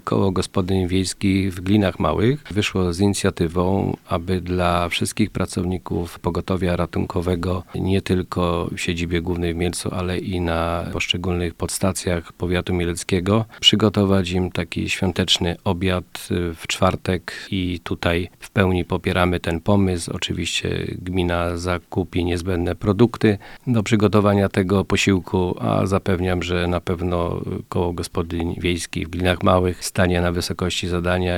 Wiedząc ile energii i wysiłku muszą włożyć osoby, które na co dzień są narażone na zakażenie wirusem, koło gospodyń przygotuje dla ratowników przedświąteczny obiad wielkanocny. Mówi wójt gminy Borowa Stanisław Mieszkowski.